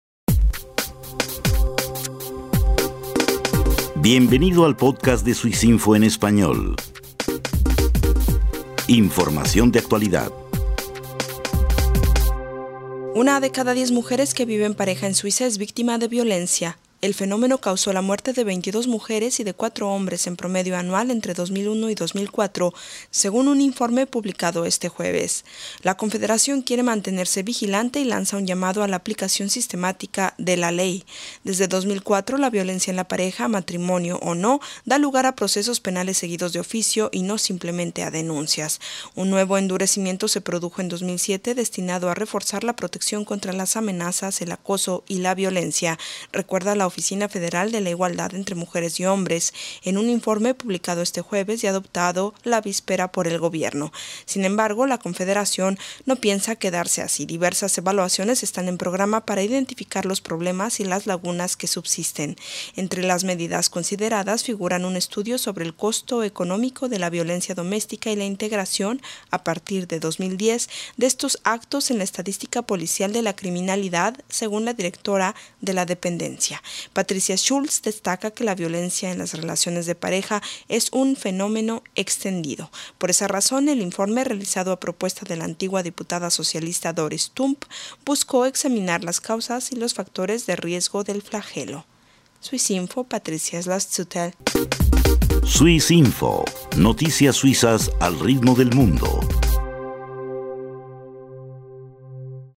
Informe sobre violencia doméstica.